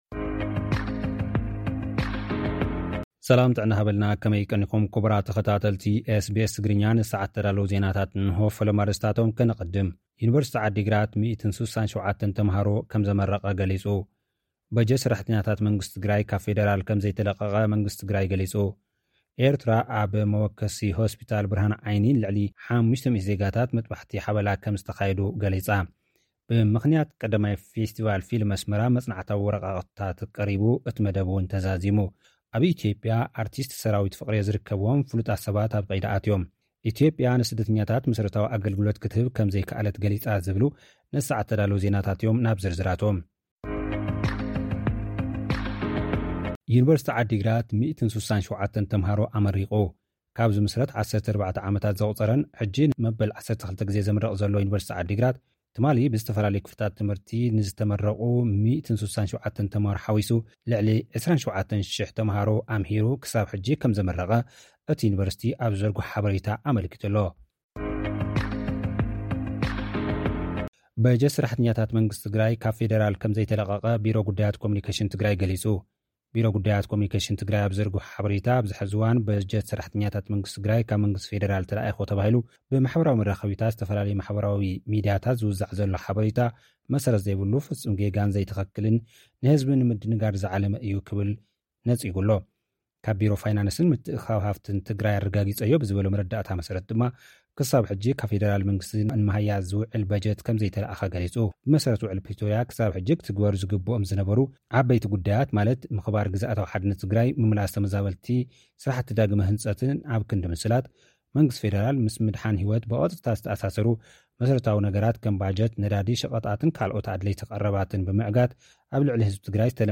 SBS Tigrinya Homeland Report